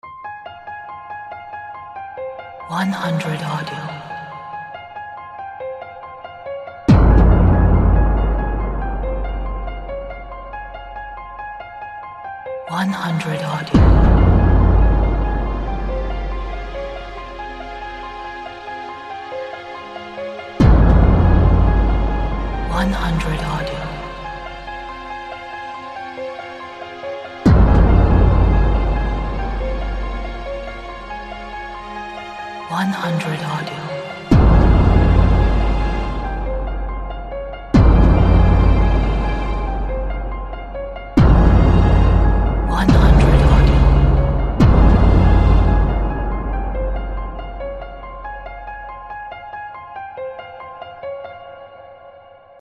This composition is suitable for a trailer, a thriller film.